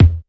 Kick_25.wav